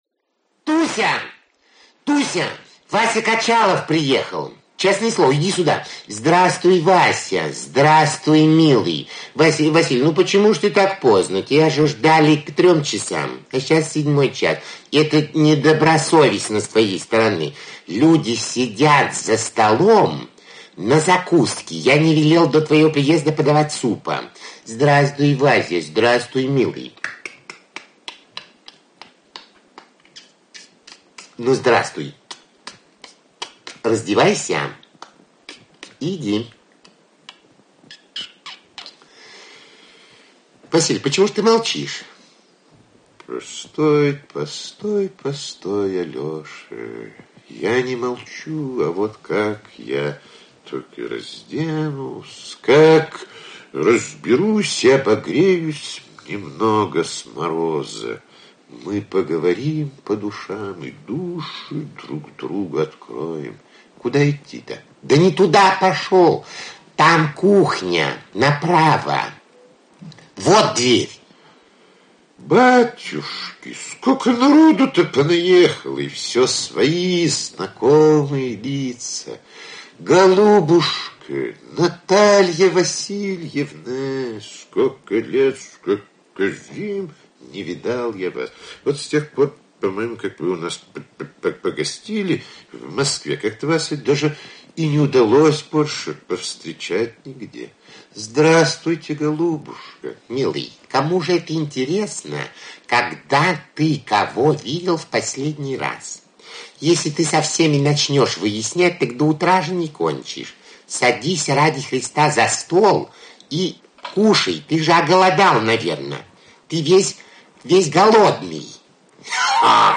Aудиокнига Качалов в гостях у Толстого Автор Ираклий Андроников Читает аудиокнигу Ираклий Андроников.